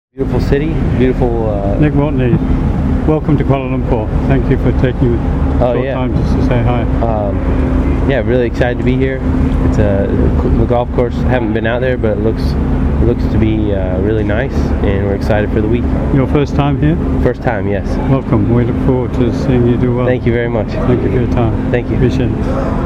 MGTA interviews Nick Watney